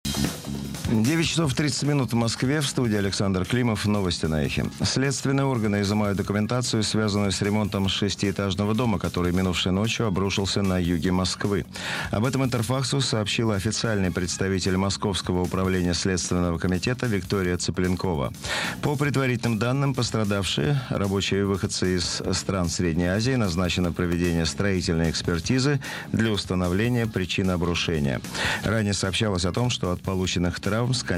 FM-радио
Есть поддержка RDS, есть возможность записи звука с эфира.
Качество приема сигнала на очень хорошем уровне, едва ли не лучшее, из всего, что я встречал за последнее время.